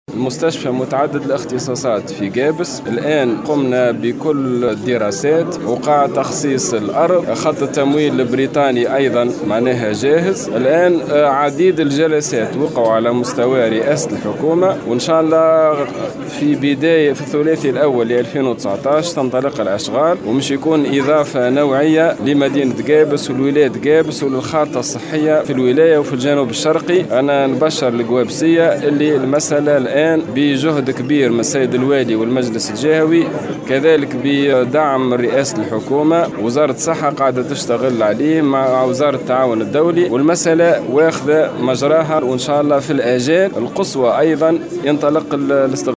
وقال وزير الصحة في تصريح